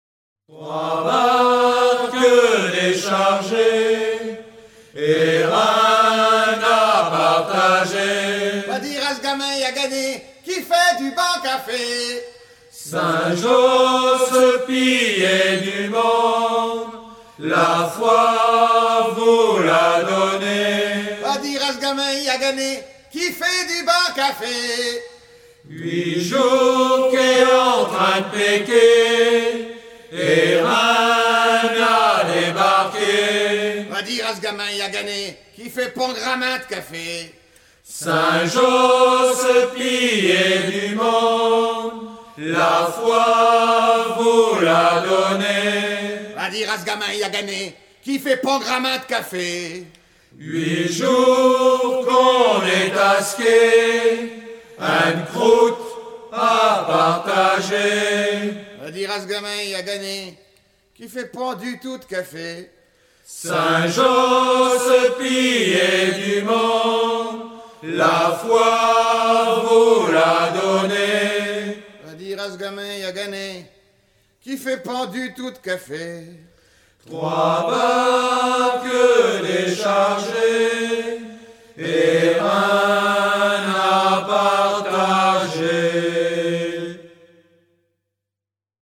Version enregistrée dans un repas de famille à Etaples vers 1980